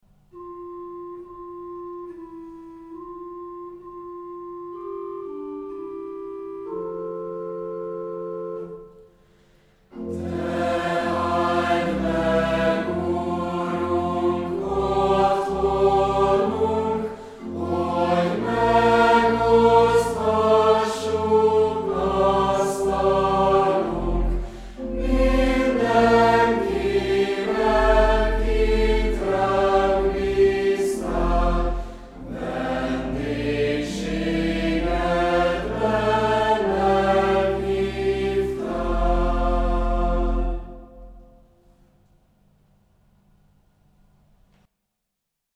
A kánon „kifutós kánon”, azaz záráskor mindegyik szólam végigénekeli a teljes versszakot (fokozatosan egyre kevesebb szólam szól), majd mindenki közösen énekelheti az „Áment” egy (nagyobb kotta) vagy több szólamban (az apró kottákat is megszólaltatva).